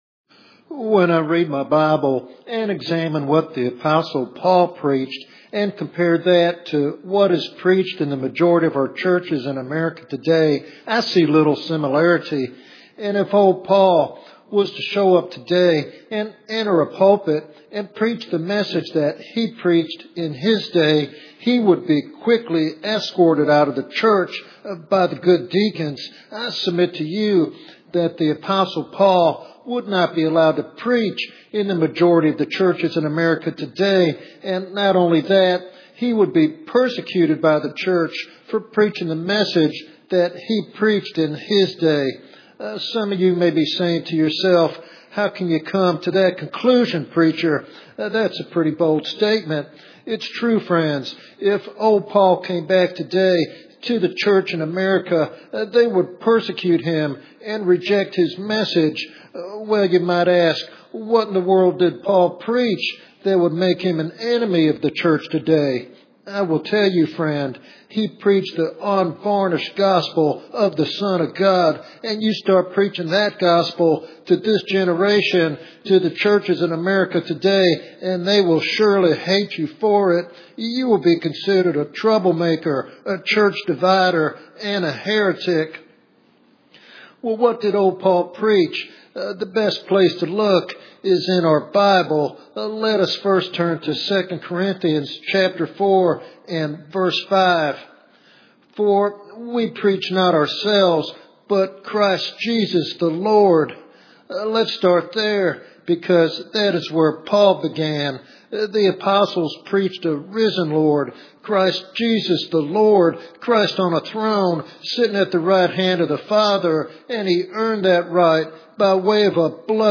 He calls for a return to preaching the full counsel of God with boldness and conviction, even at the cost of suffering. This sermon is a call to faithfulness and courage for preachers and believers alike.